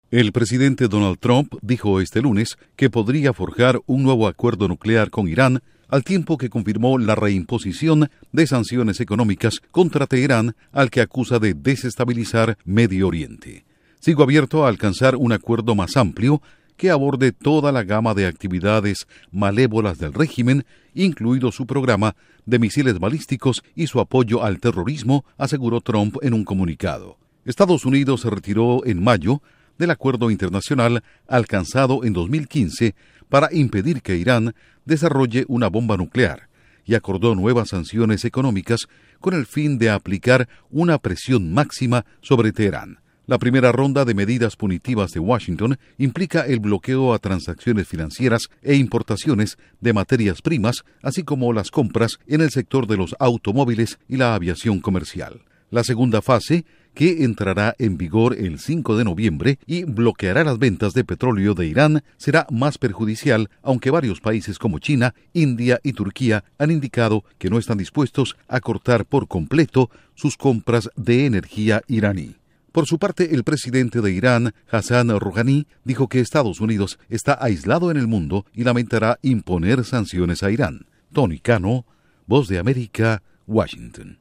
Trump reactiva sanciones contra Irán; el presidente iraní dice que EE.UU. se arrepentirá. Informa desde la Voz de América en Washington